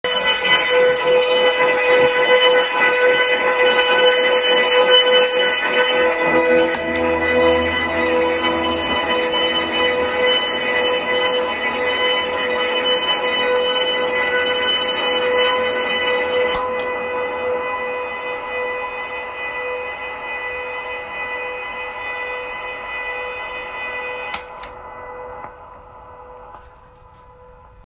Loud sound (like oscillation) when pedals on (sound attached)
I attached, but here's the raw webpage: Attachments amp_noise.mp3 amp_noise.mp3 444.6 KB · Views: 129
I have 6 pedals on a board, I think maybe 4 or 5 are on for my example, then you can hear as I start clicking them off... the annoying oscillation goes down in volume.